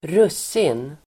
Uttal: [²r'us:in]